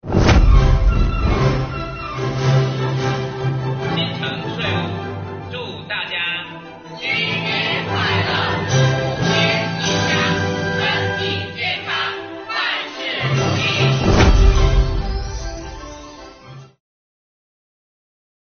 最后，再来查收来自他们的新年祝福吧。